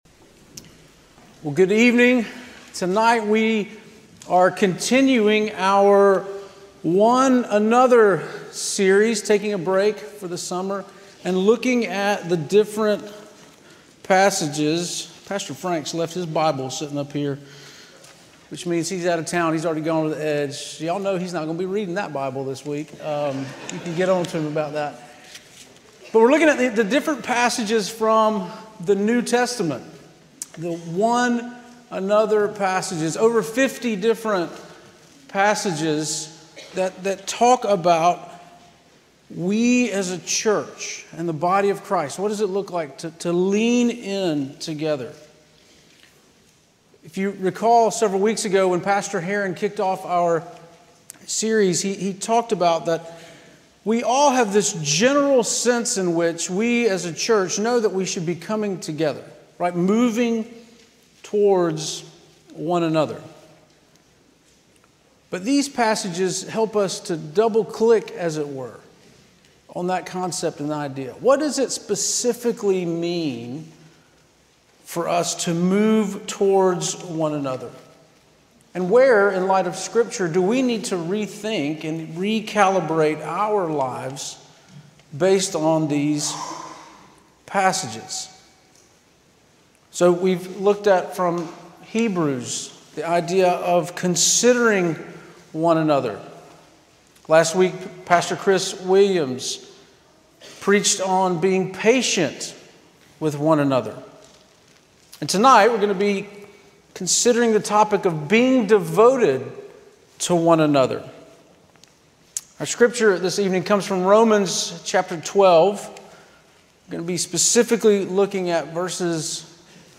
Sermons - First Presbyterian Church of Augusta